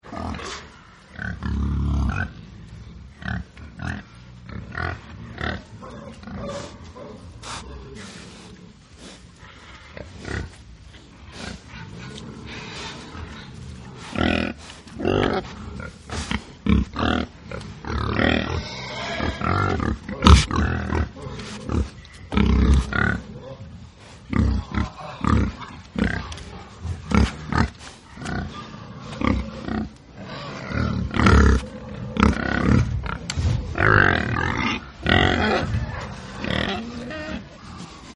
На этой странице собраны натуральные звуки диких кабанов: от хрюканья и рычания до топота копыт по лесу.
Кабаны, выращиваемые на ферме